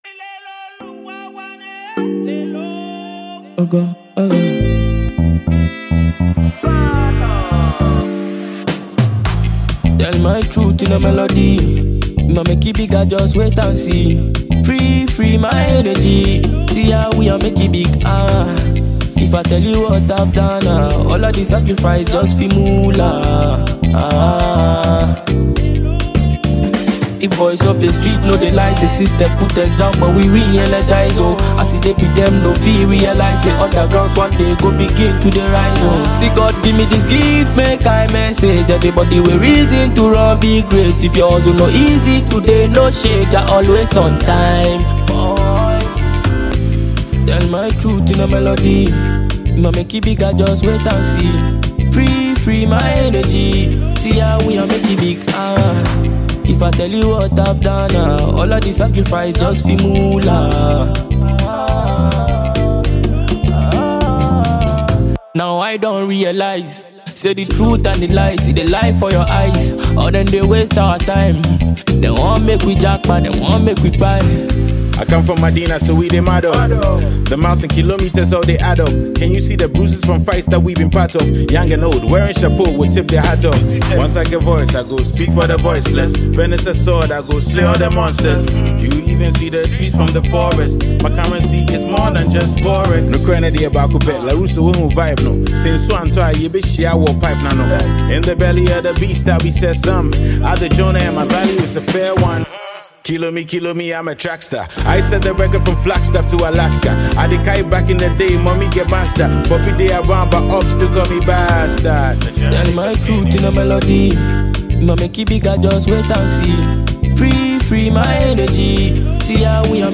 Ghanaian dancehall